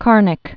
(kärnĭk)